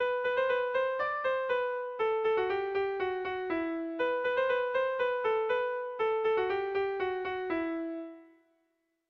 Irrizkoa
ABA2B